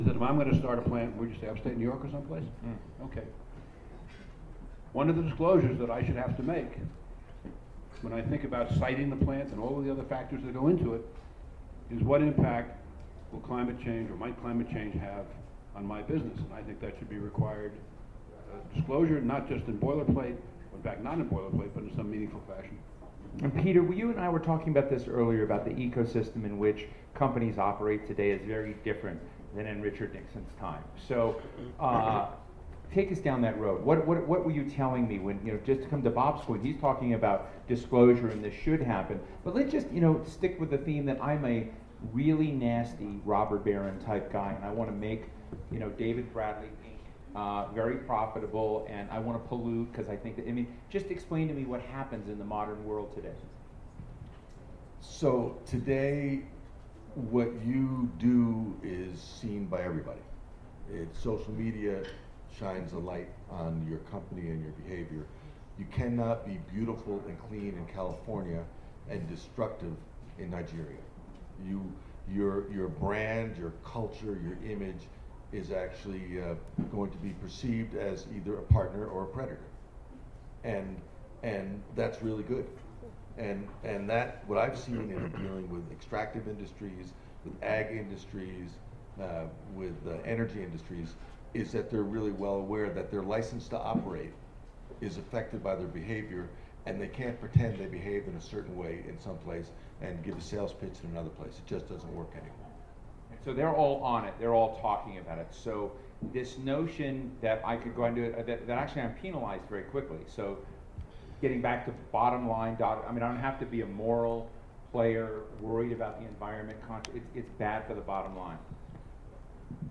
( flyer ) Aspen Ideas Festival , June 30, 2016 panel discussion assembled by The Atlantic , sponsored by The Walton Family Foundation Former Treasury Secretary Robert Rubin calls for a Green GDP , cites carbon pricing as the critical vehicle to get there.